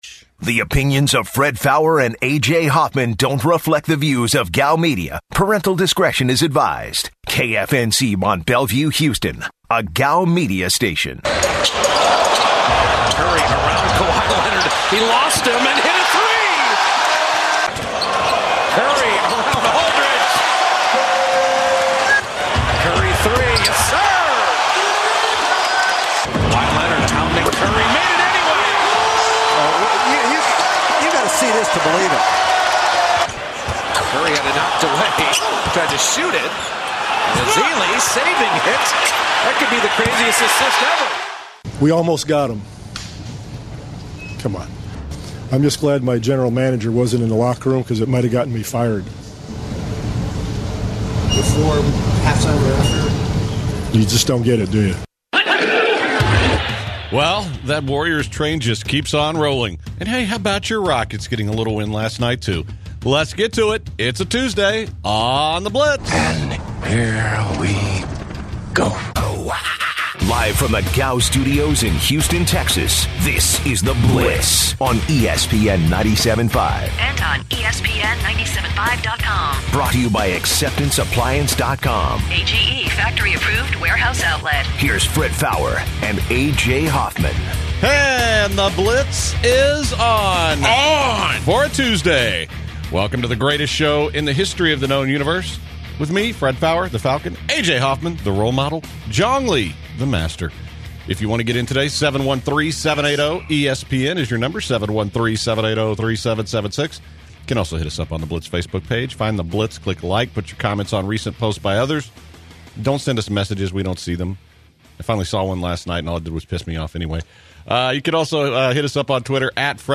The guys also speak with callers about the role of their show, Bernie Sanders, the 2016 election season and election voting strategies for a third party.